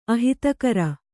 ♪ ahitakara